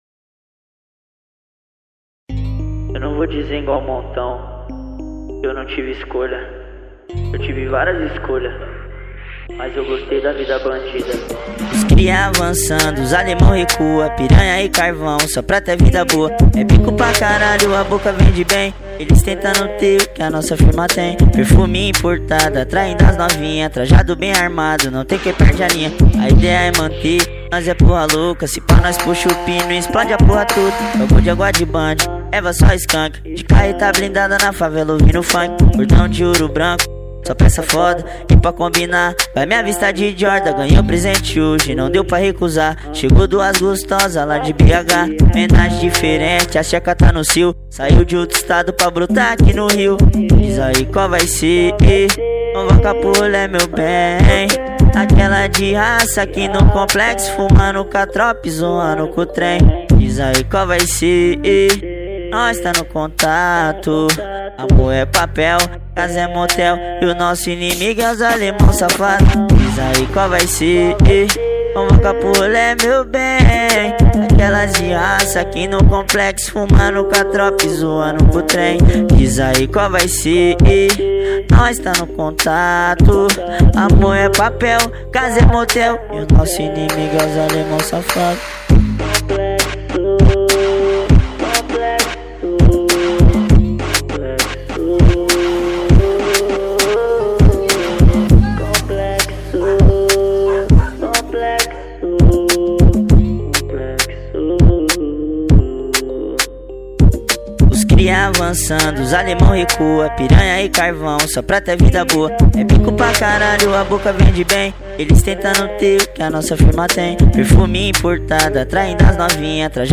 2024-10-01 19:55:24 Gênero: Funk Views